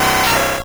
Cri d'Insécateur dans Pokémon Or et Argent.